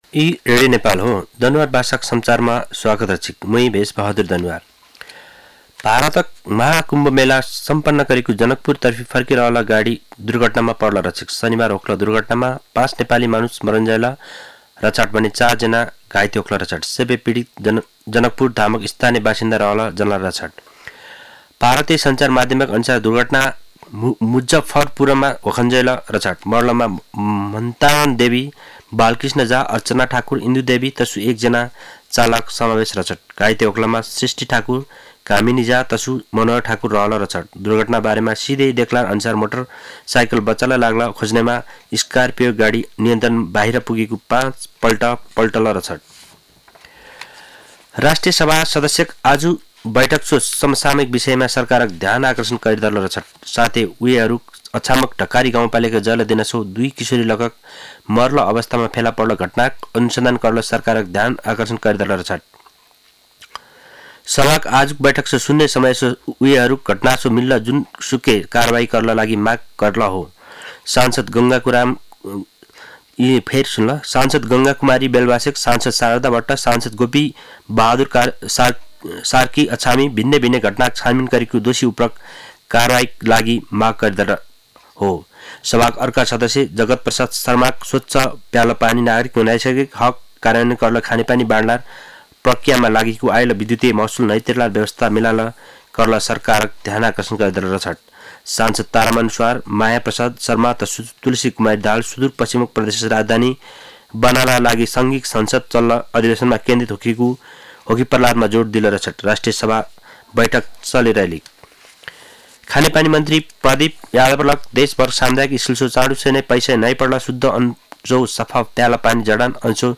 दनुवार भाषामा समाचार : २१ माघ , २०८१
danuwar-news.mp3